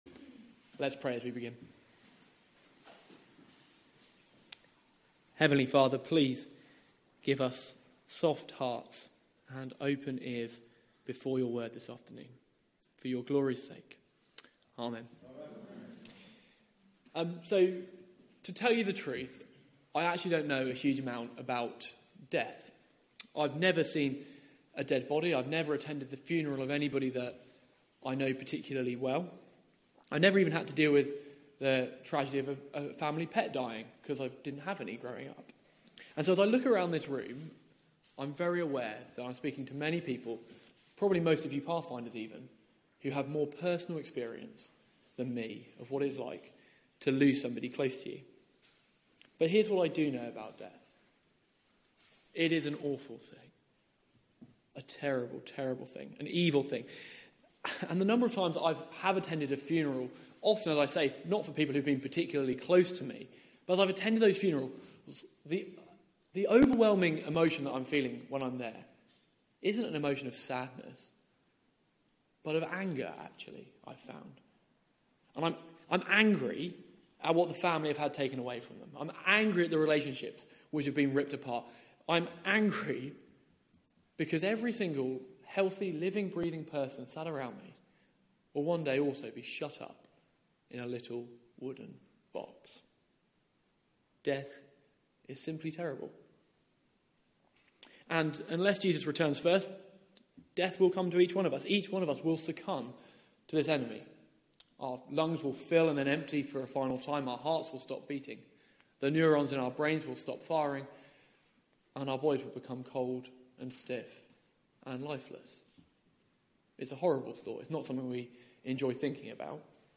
Media for 4pm Service on Sun 09th Oct 2016 16:00 Speaker